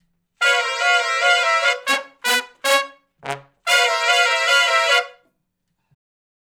046 R&B Riff (C#) har to uni.wav